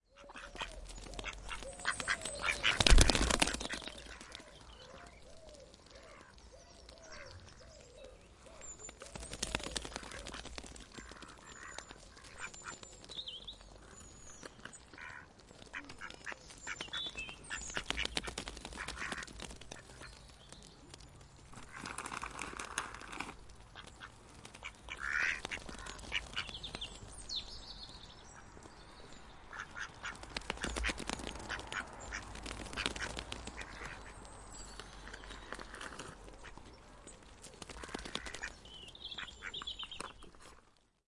鸭子在跑
描述：一群鸭子在麦克风前来回奔跑。其他的噪音包括沙沙作响的食品袋，知更鸟，带领的鸽子和一些交通。
标签： 鸭子 拍打 跑步 鸭子 嘎嘎
声道立体声